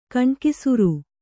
♪ kaṇkisura